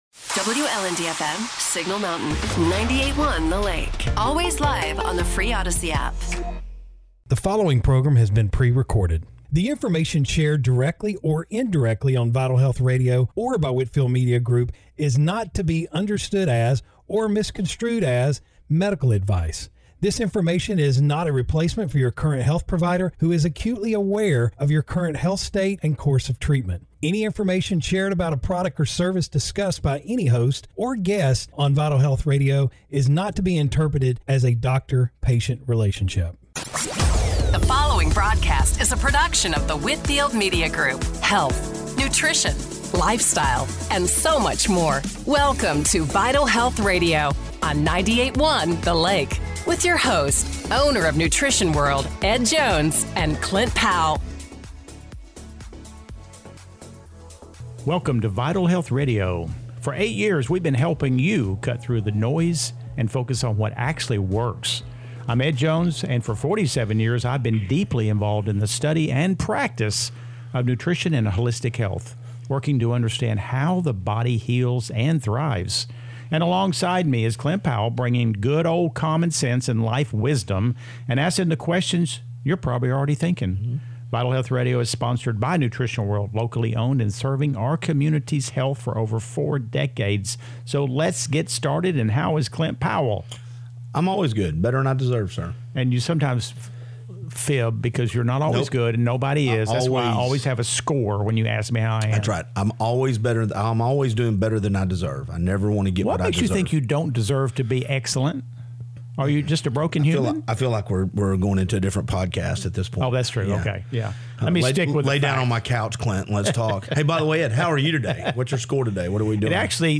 Broadcasting from the: Nooga Dentistry Studio